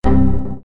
パソコンを使っているとき、誤った操作をおこなうと鳴るWindowsXP風エラー音。